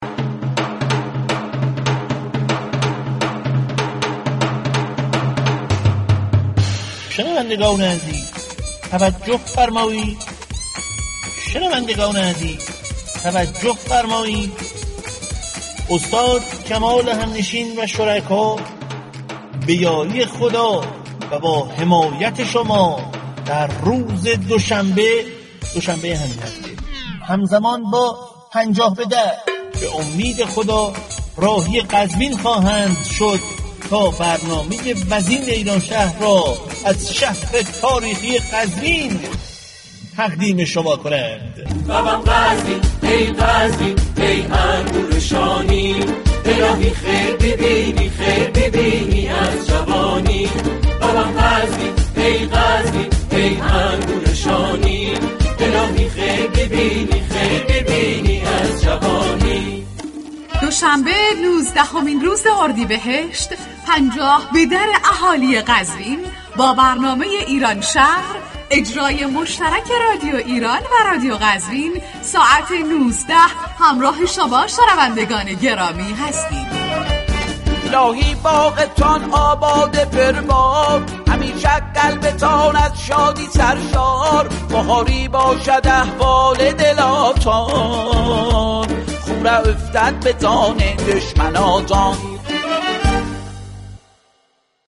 برنامه «ایرانشهر» رادیو ایران امروز دوشنبه 19 اردیبهشت، به مناسبت «پنجاه به در» قزوینی ها و روز باران ، از قزوین تقدیم می شود.